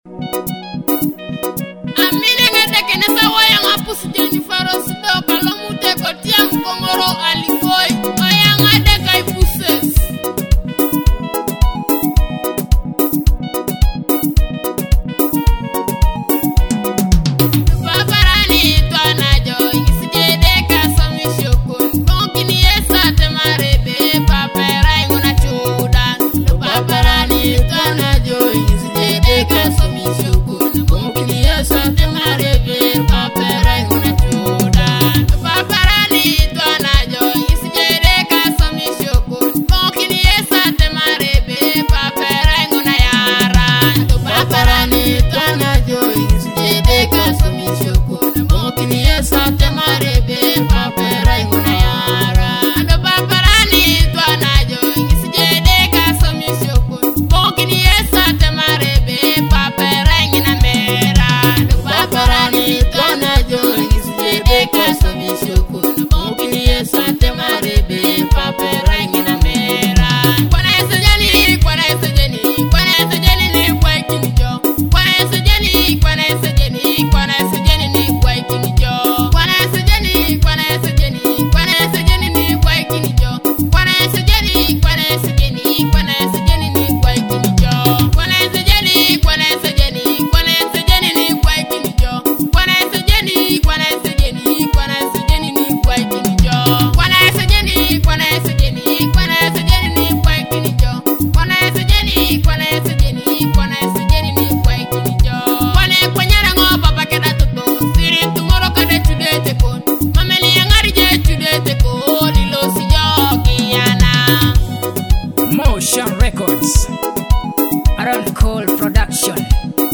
soul-stirring melodies
gospel masterpiece